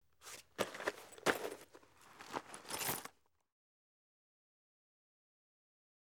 household
Grab Cloth Make-Up Bag with Objects Rattle Inside